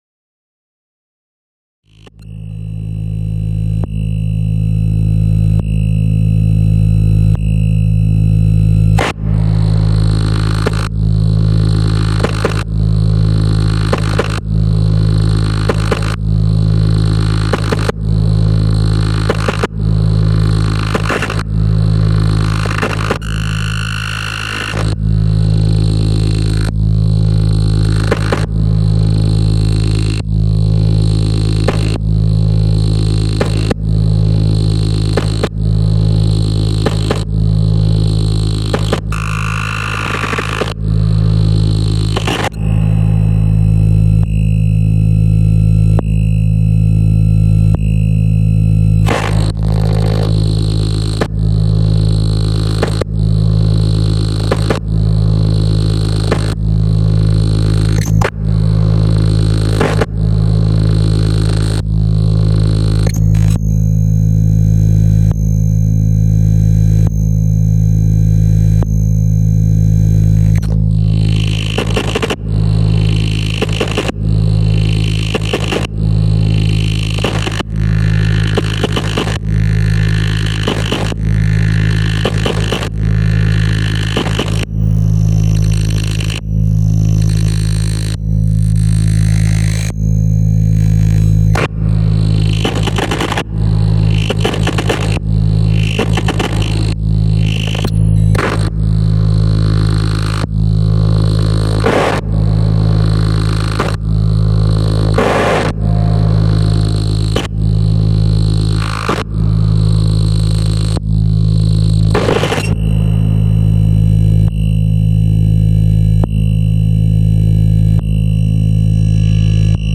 first sounds with the new setup (serge). there is a lot to learn…